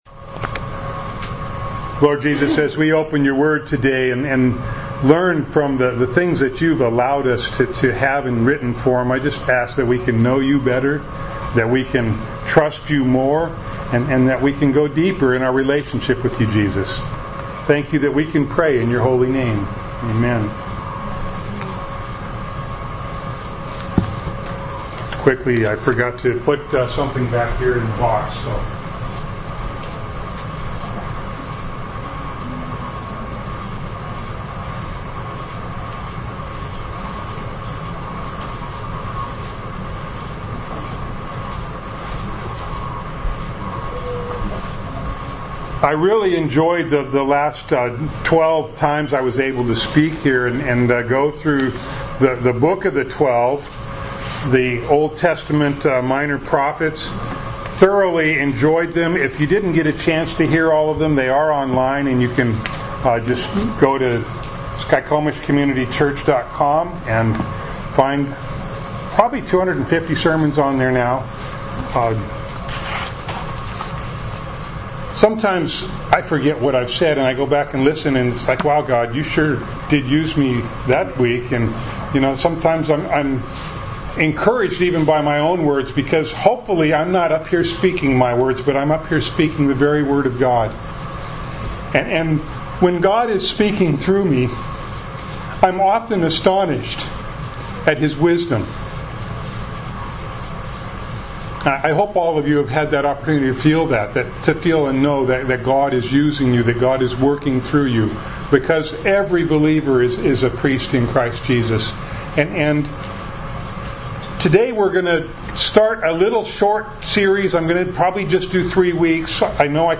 Jude 1:1-4 Service Type: Sunday Morning Bible Text